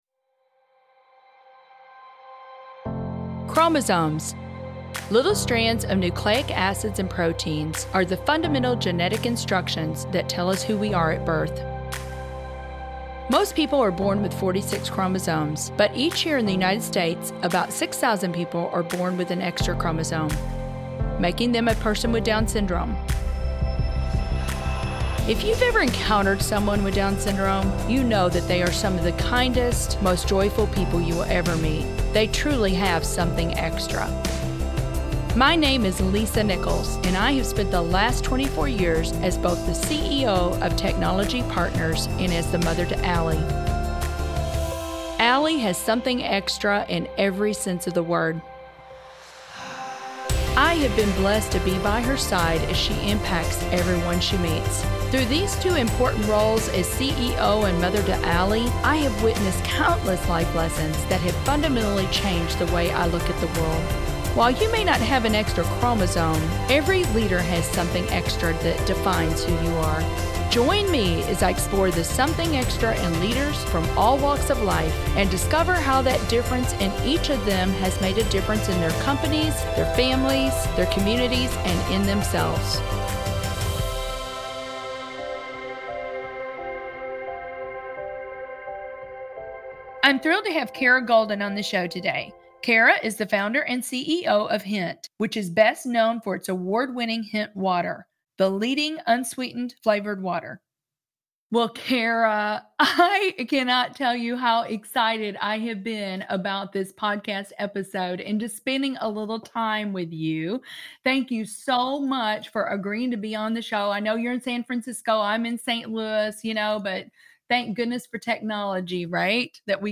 Lisa Nichols, Host